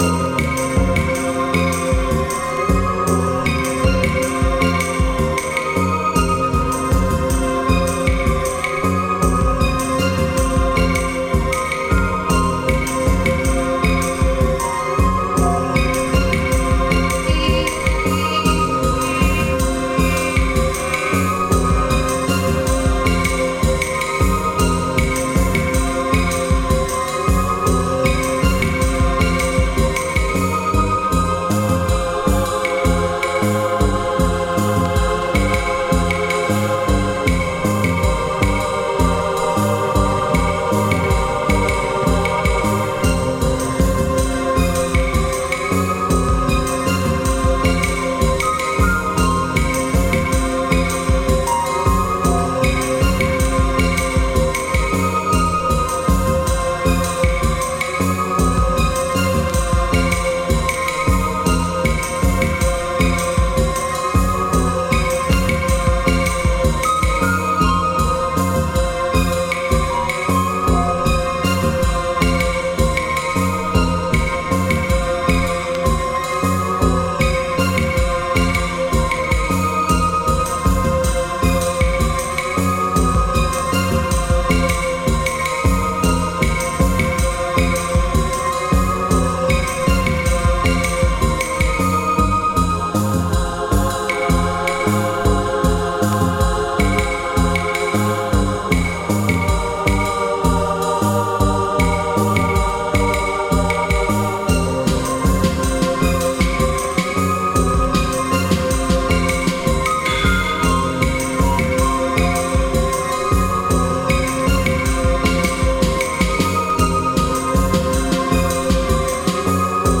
ジャンル(スタイル) DEEP HOUSE / CLASSIC HOUSE / BALEARIC HOUSE